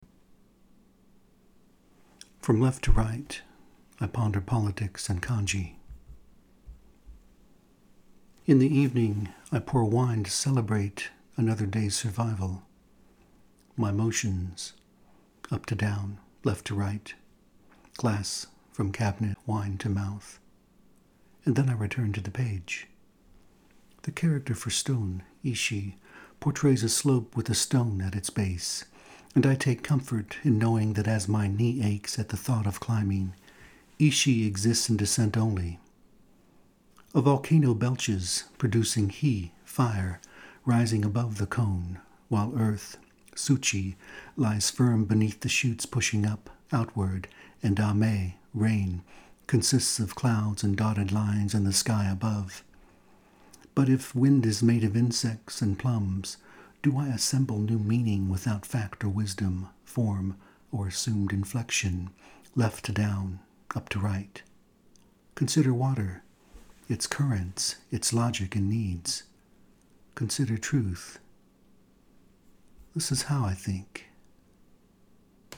Listen to a reading of the poem by the poet: